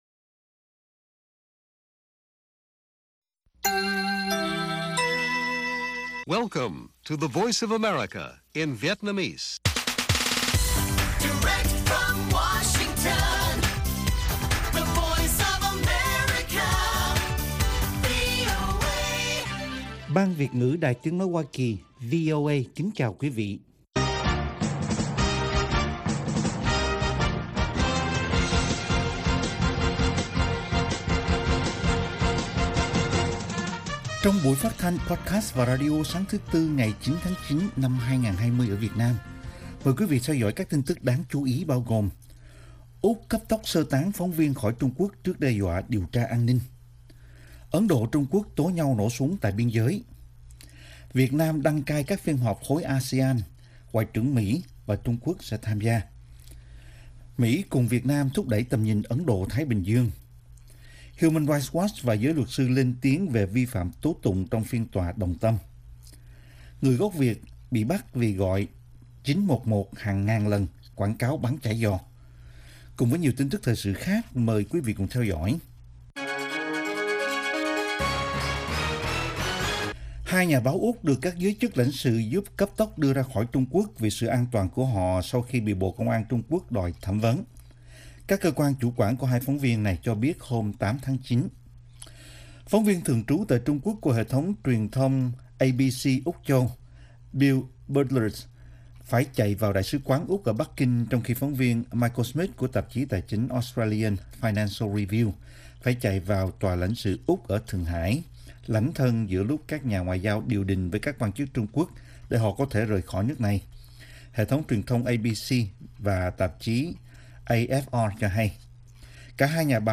Bản tin VOA ngày 9/9/2020